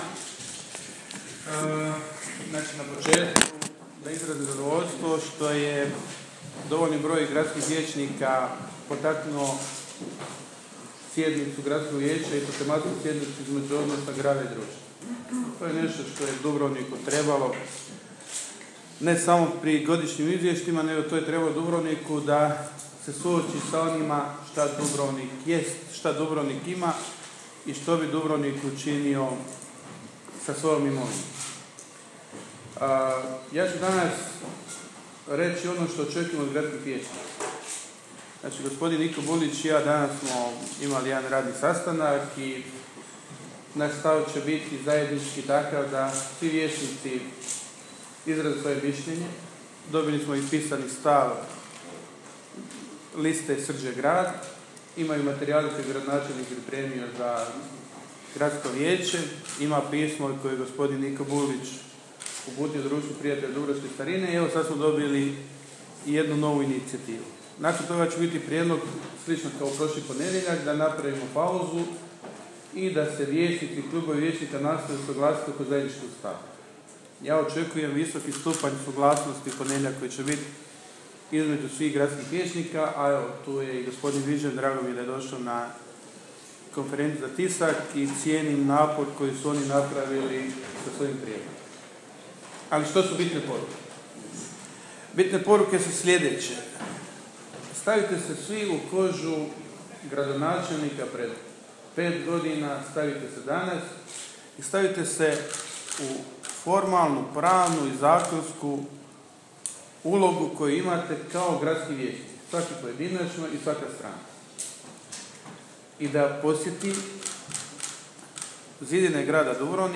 Uoči tematske sjednice Gradskog vijeća o odnosu Grada Dubrovnika i Društva prijatelja dubrovačke starine gradonačelnik Andro Vlahušić održao je konferenciju za novinare pritom se žestoko obrušivši na čelnike DPDS-a.